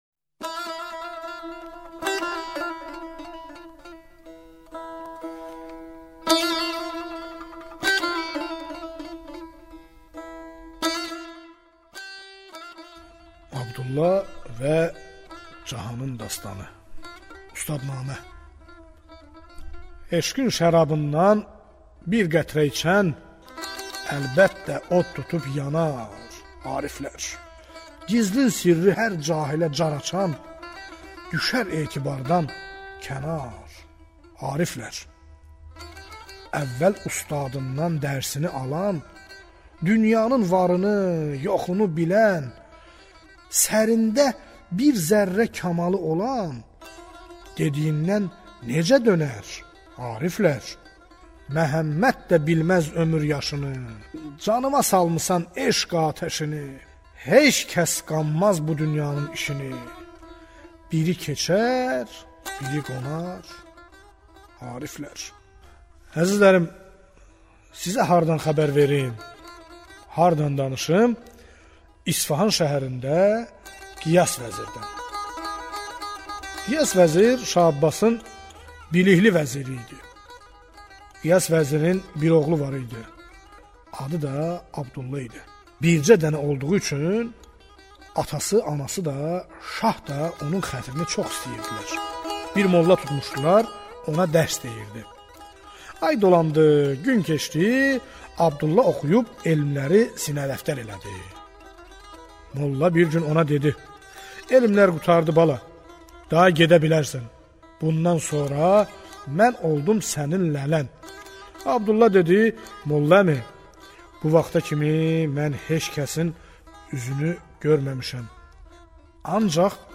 Azeri dastan